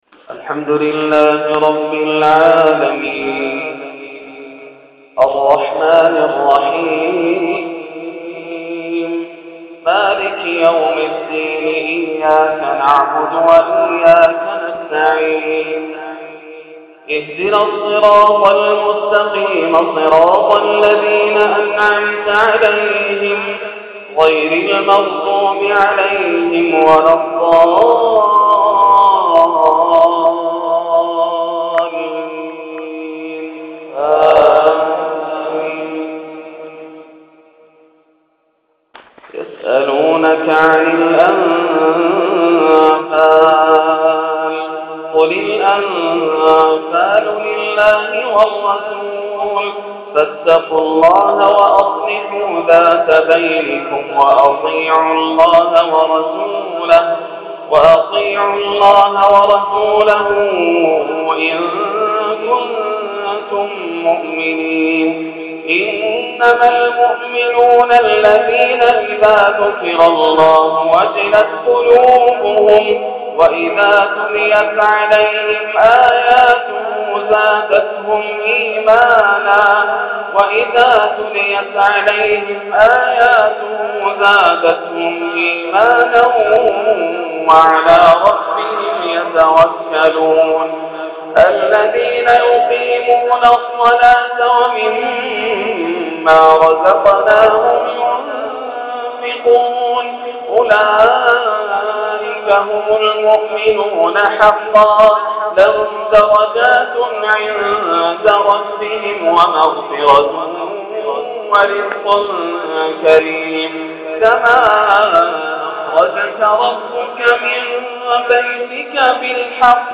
تلاوة لفواتح سورة الأنفال | 2-3-1431 > عام 1431 > الفروض - تلاوات ياسر الدوسري